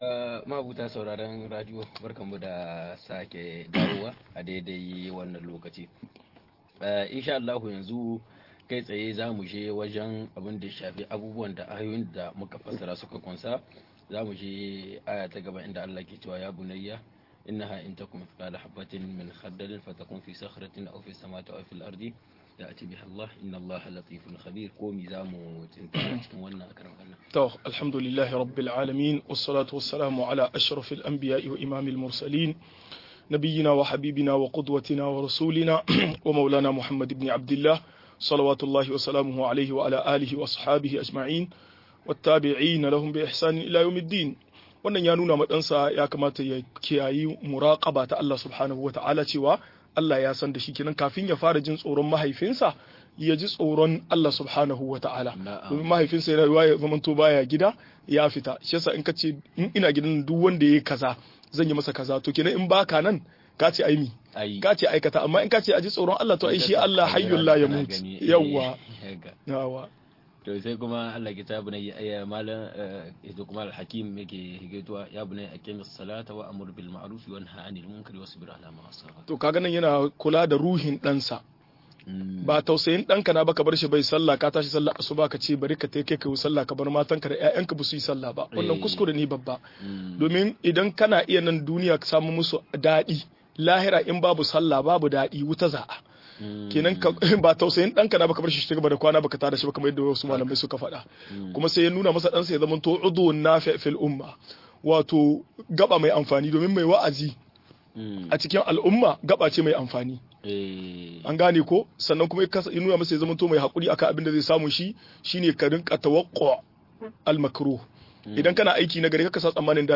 Tarbiyya daga mahaifiyar Mariam - MUHADARA